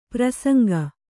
♪ prasaŋga